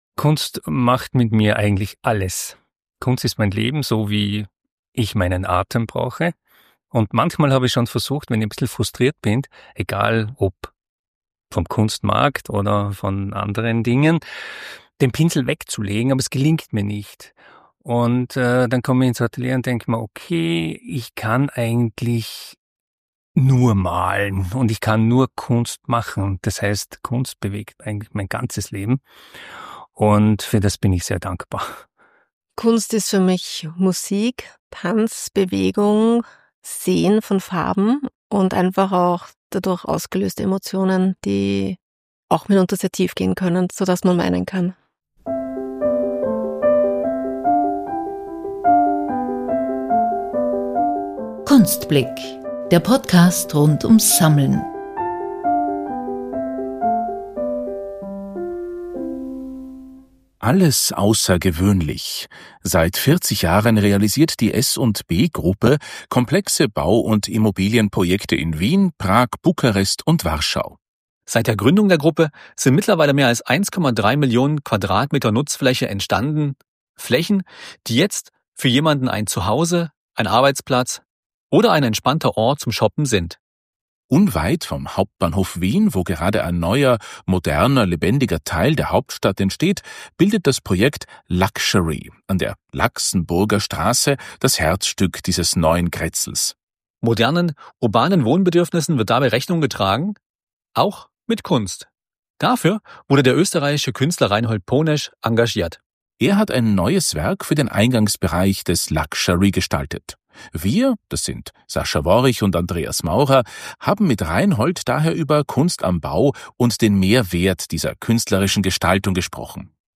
Getroffen haben wir die beiden im Büro von S&B – noch ohne Kunstwerk.